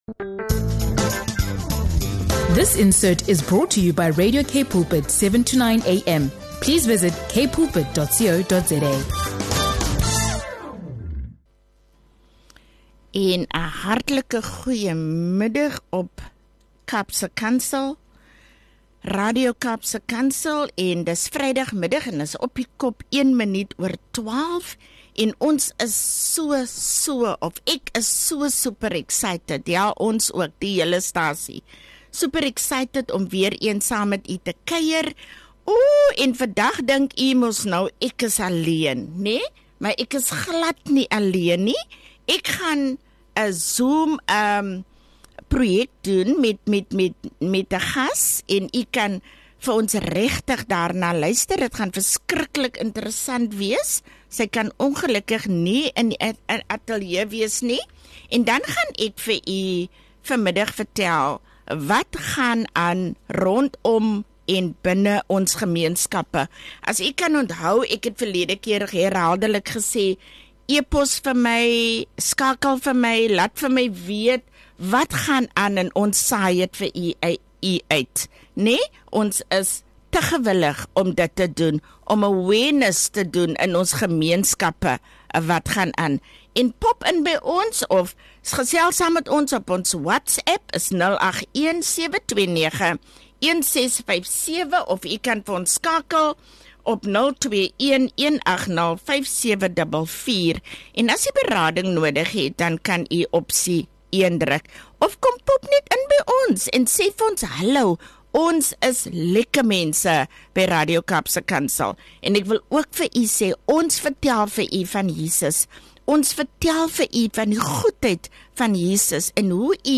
We also hear about their award-winning Friendly Neighbor Program, which brings care directly into the homes of vulnerable seniors who cannot access services themselves. You’ll hear moving testimonies of lives transformed—from a young boy who received support through early education programs and later fulfilled his dream of becoming a police officer, to elderly community members who found belonging and dignity after years of isolation.